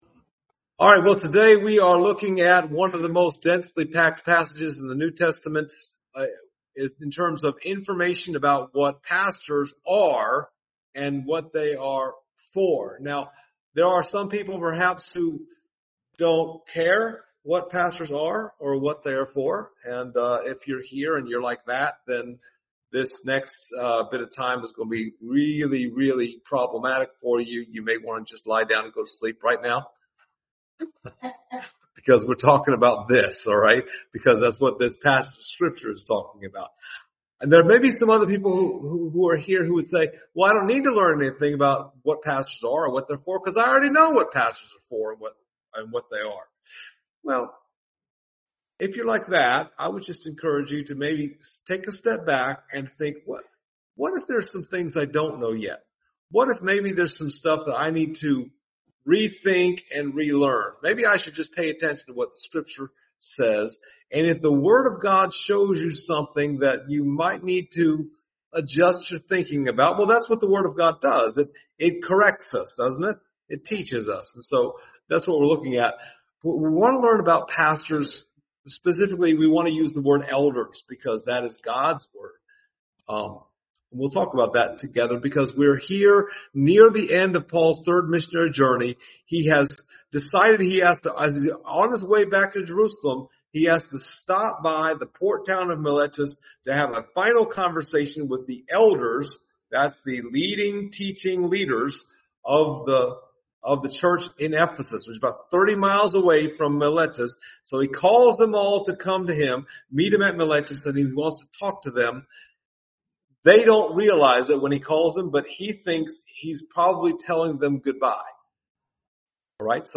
Service Type: Sunday Morning Topics: elders , pastors , sheep , wolves